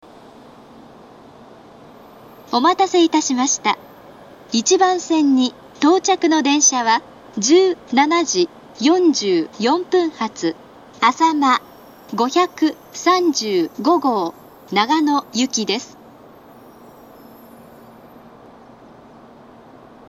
１番線到着放送
annakaharuna1bansen-totyaku.mp3